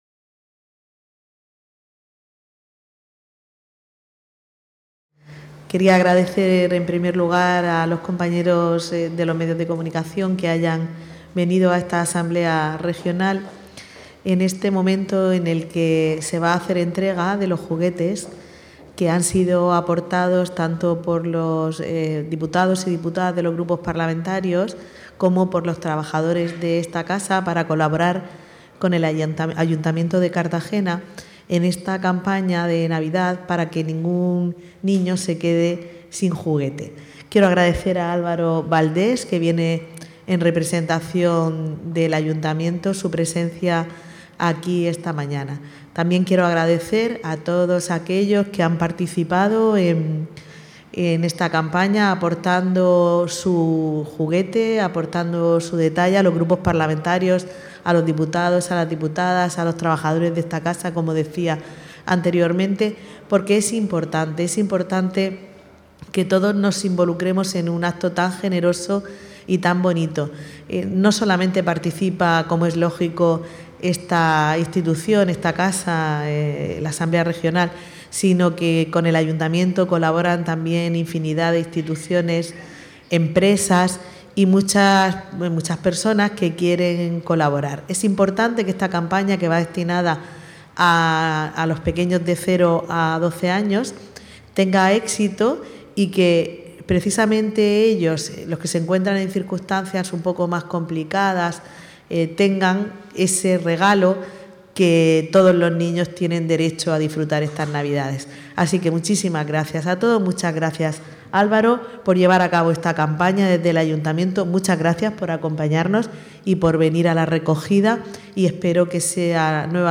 • Declaraciones de la presidenta de la Asamblea Regional, Visitación Martínez, y del concejal del Ayuntamiento de Cartagena, Álvaro Valdés, durante la entrega de juguetes de la Campaña "Juguetea" en la Cámara Parlamentaria